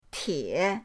tie3.mp3